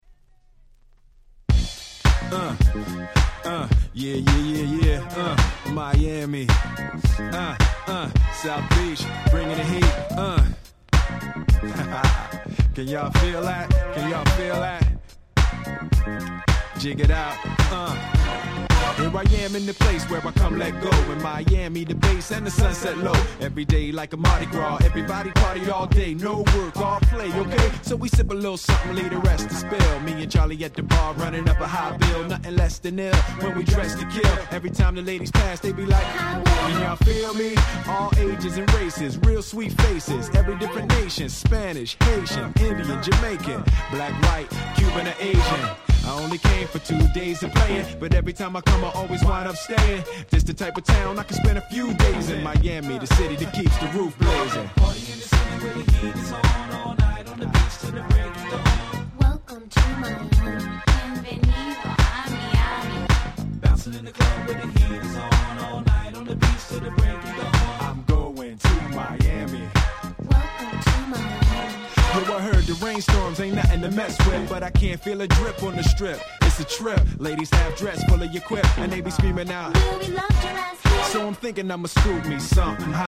97' Super Hit Hip Hop LP !!